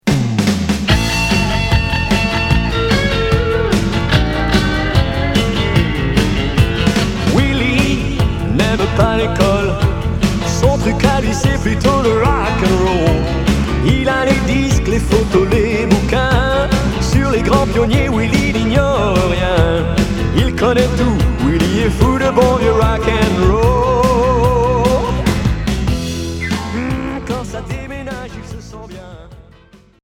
Rock Huitième 45t retour à l'accueil